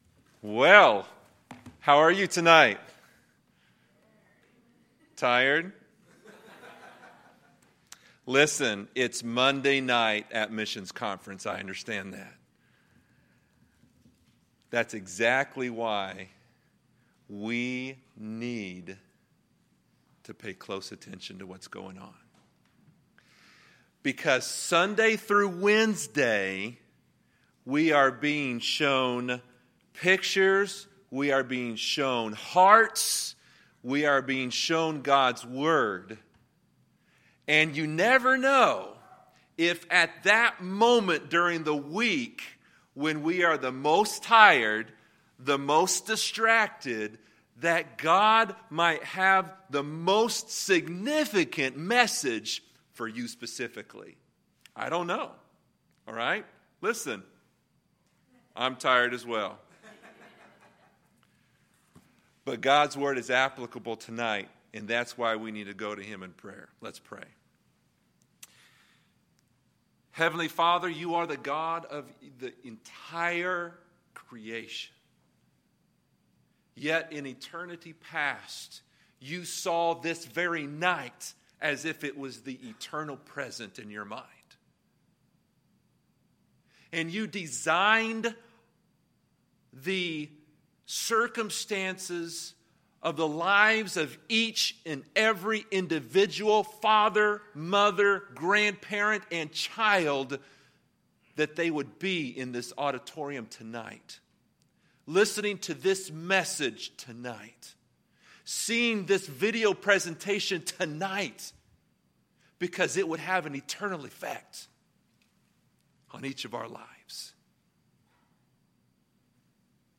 Monday, September 25, 2017 – Missions Conference Monday PM Service